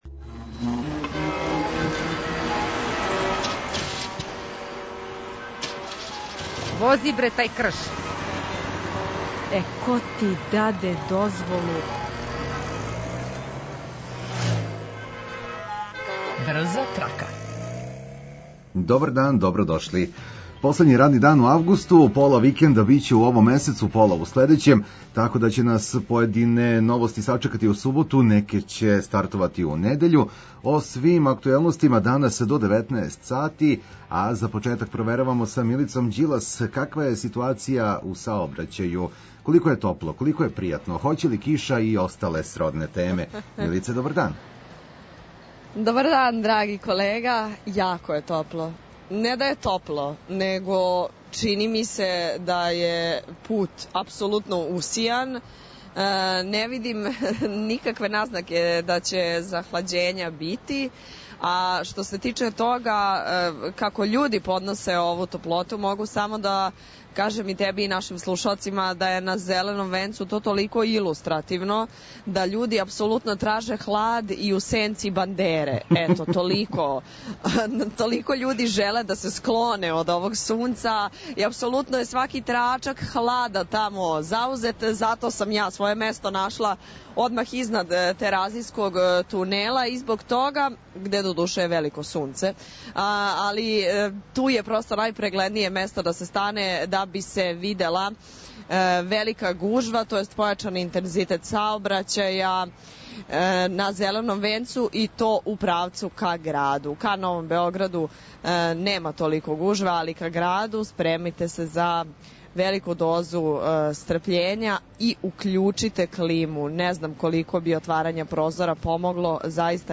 Репортер